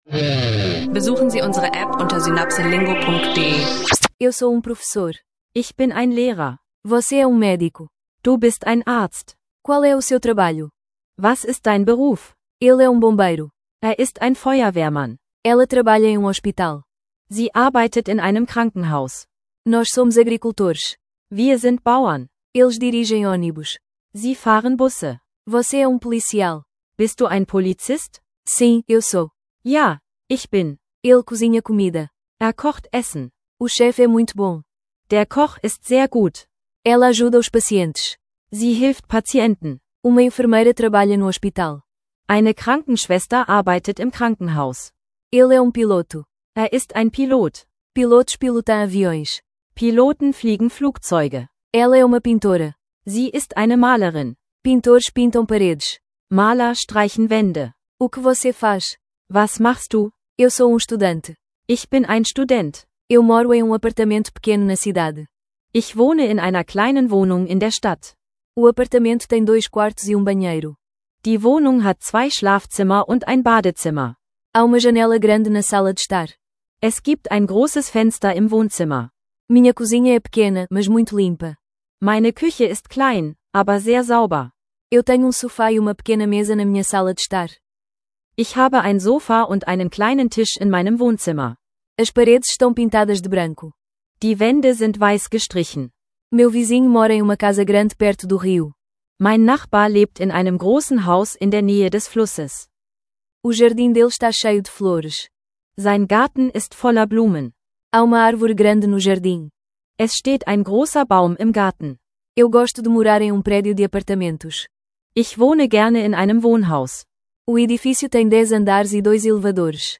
Diese Episode des SynapseLingo Portugiesisch lernen Podcasts bietet einen interaktiven Portugiesisch Sprachkurs, der sich perfekt für Anfänger eignet.